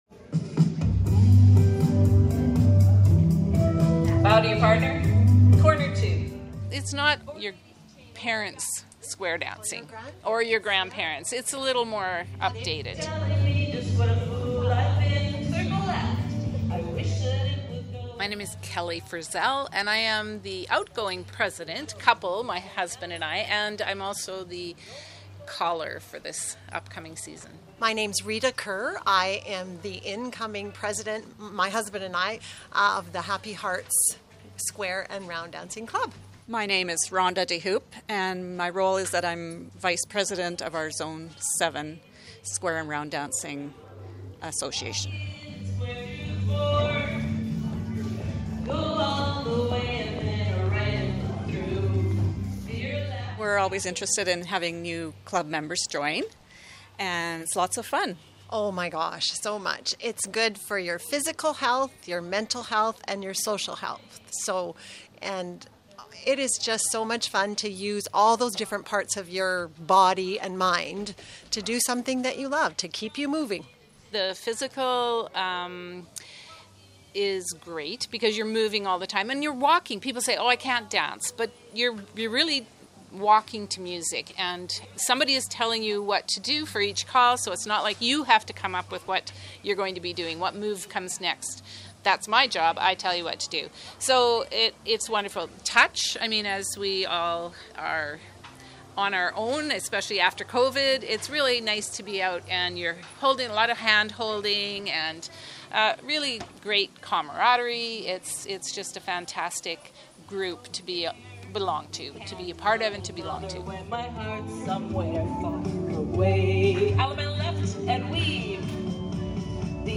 Gallery Great Dancer interview on – The Morning Edition at 745 am.
Square-dancing-Mp3.mp3